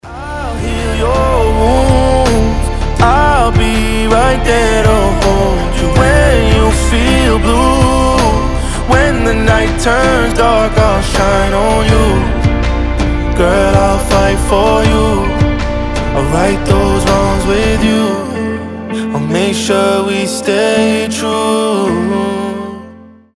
• Качество: 320, Stereo
красивые
спокойные